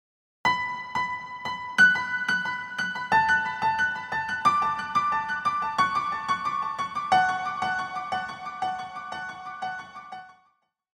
• Качество: 320, Stereo
мелодичные
без слов
инструментальные
Melodic
- японская рок-метал-группа.